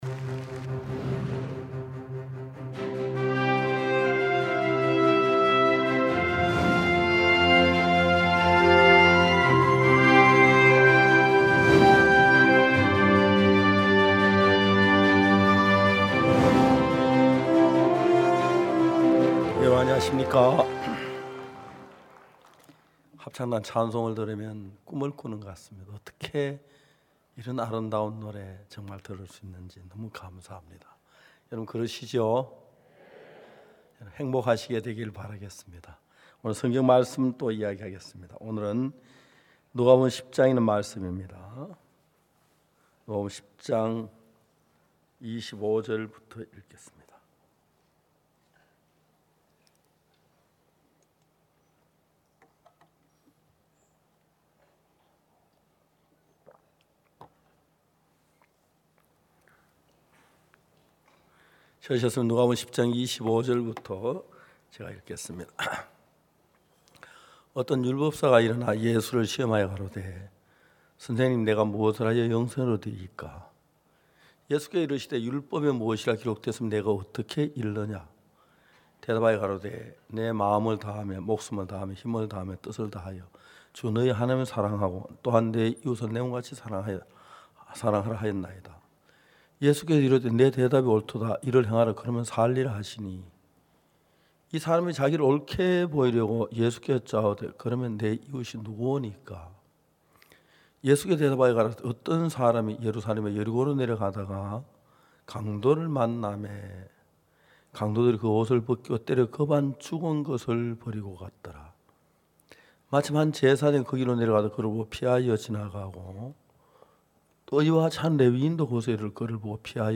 성경세미나 설교를 굿뉴스티비를 통해 보실 수 있습니다.